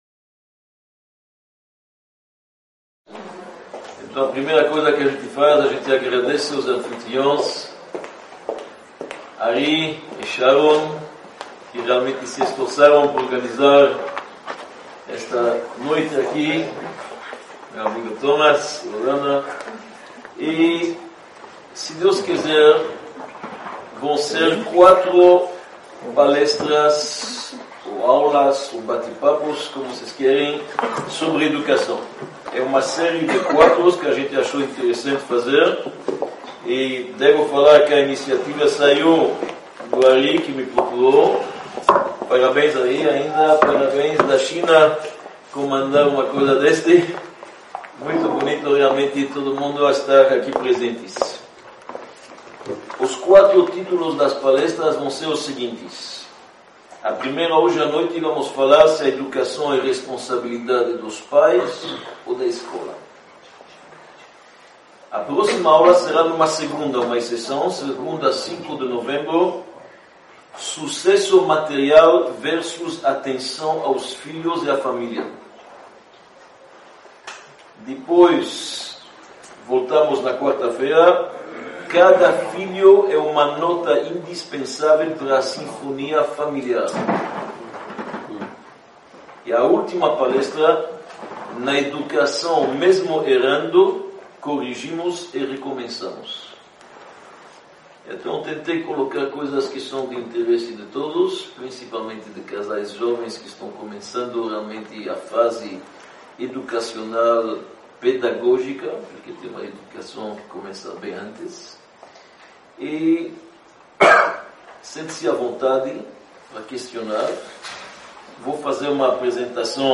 Palestra-Parte-1_-Educação-responsabilidade-dos-pais-ou-da-escola_-1.mp3